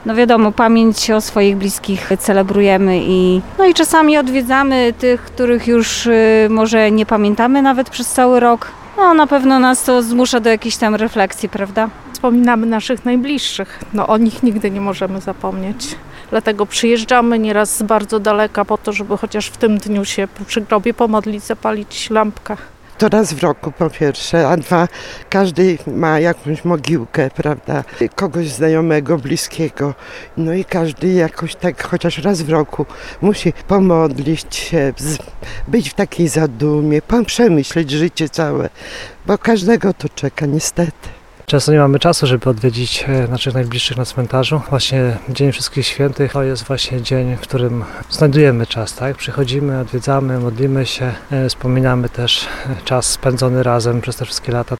– To szczególny czas – mówią ełczanie, których spotkaliśmy na jednym z cmentarzy.